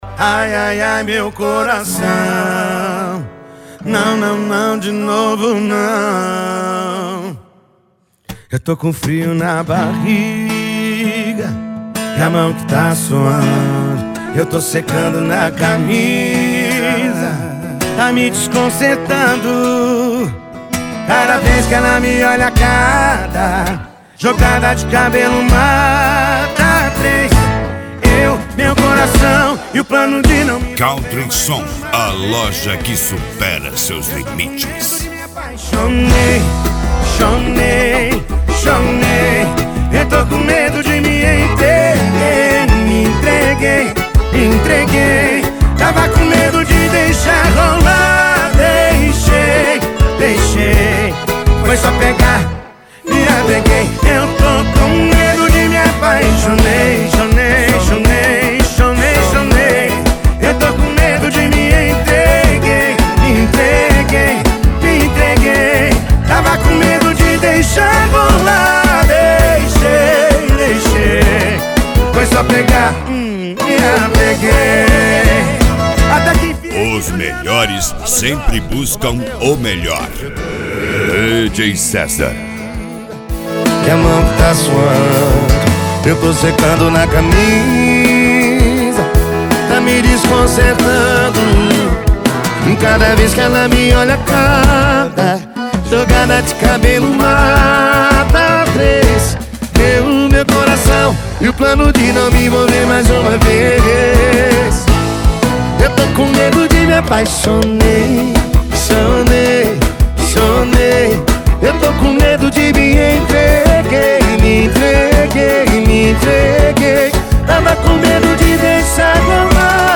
Funk
Funk Nejo
Mega Funk